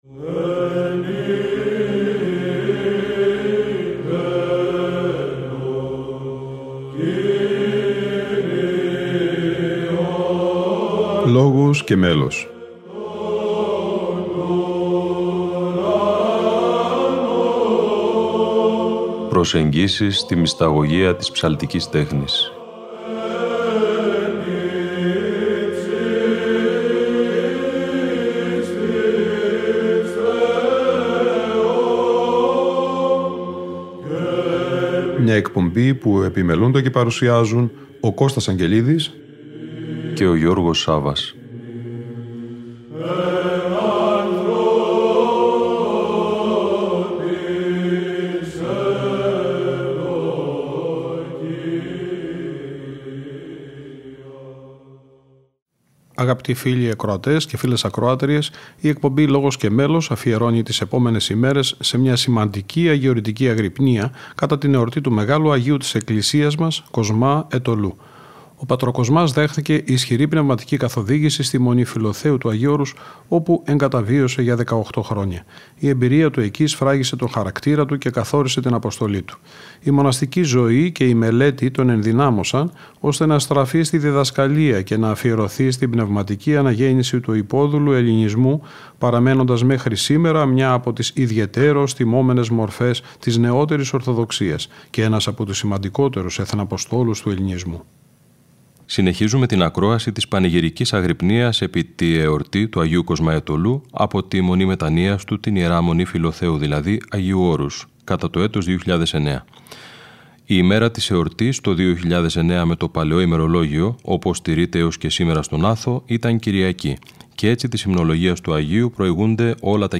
Αγρυπνία Αγ. Κοσμά Αιτωλού - Ι. Μ. Φιλοθέου 2009 (Β΄)